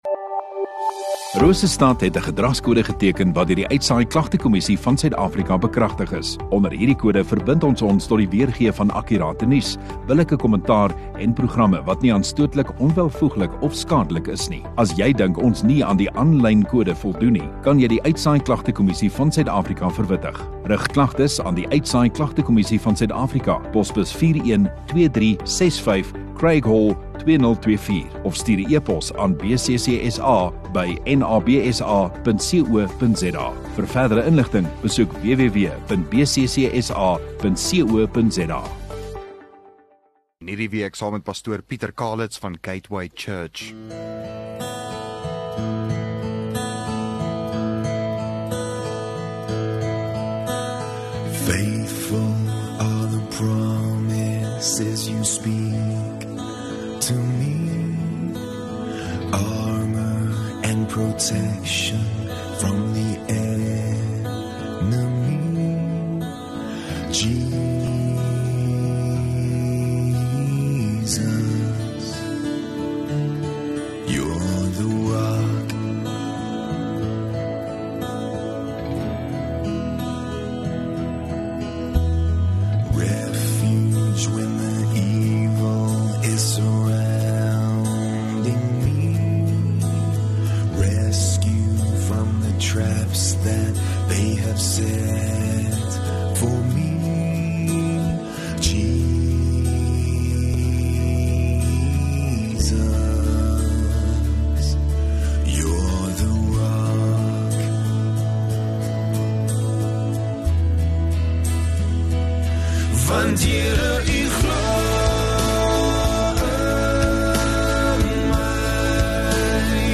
2 Jun Maandag Oggenddiens